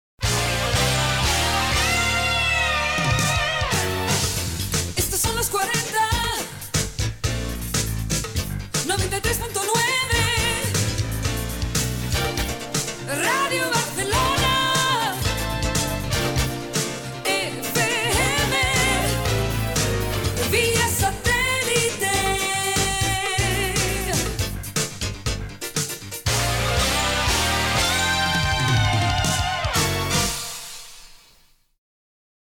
Indicatiu del programa i de l'emissora, als 93.9.